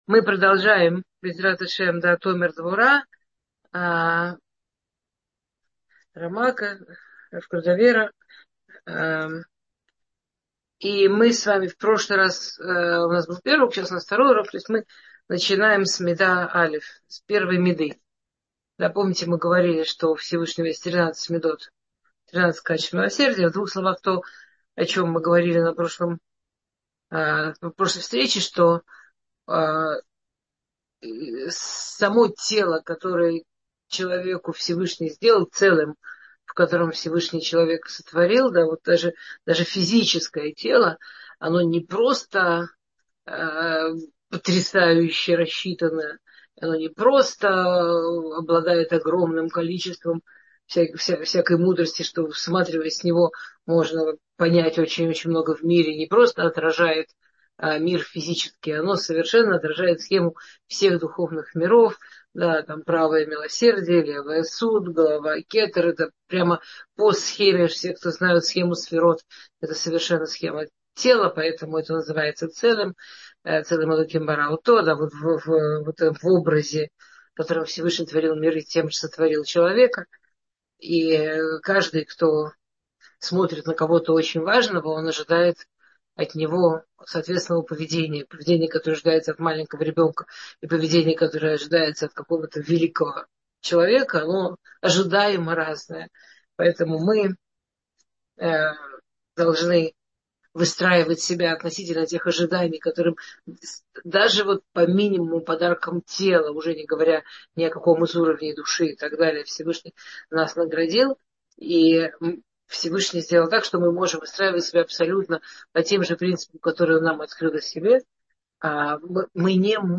Кто как Ты — слушать лекции раввинов онлайн | Еврейские аудиоуроки по теме «Мировоззрение» на Толдот.ру